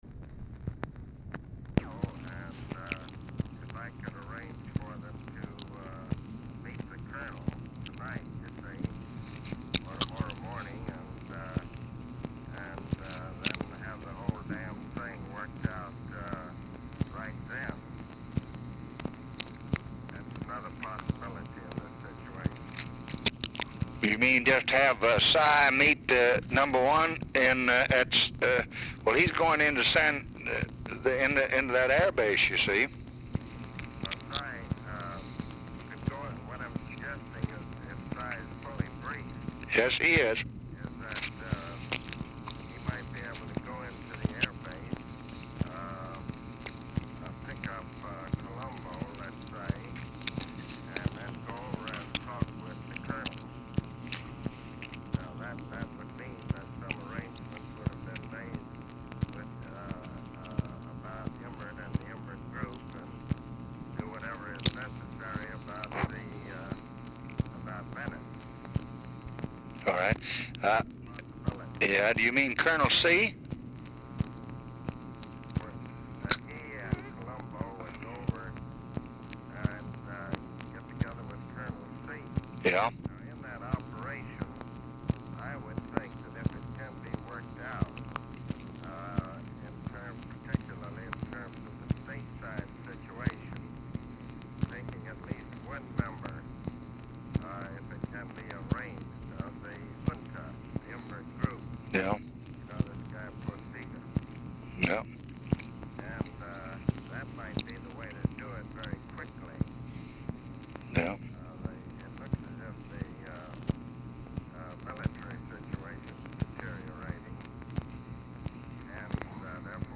Telephone conversation # 7685, sound recording, LBJ and ABE FORTAS, 5/14/1965, 8:45PM | Discover LBJ
Format Dictation belt
Location Of Speaker 1 Oval Office or unknown location
Specific Item Type Telephone conversation